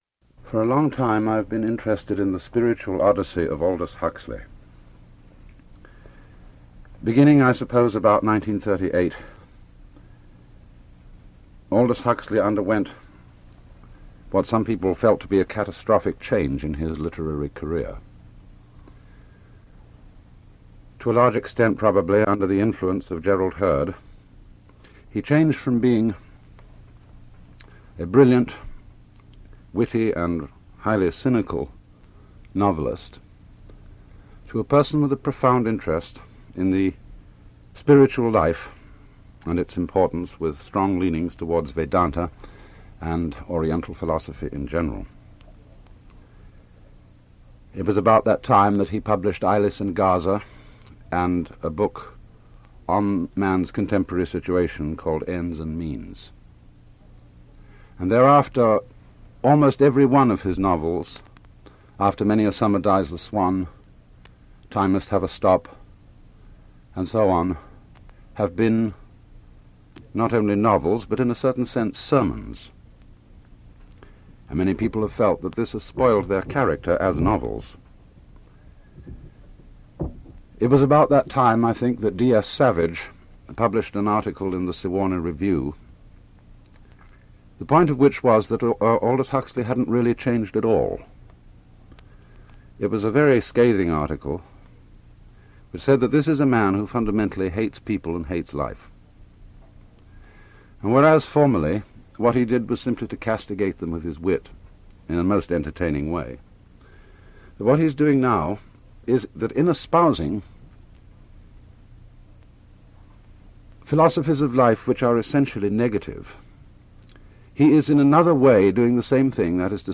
Alan Watts – Early Radio Talks – 01 – Aldous Huxley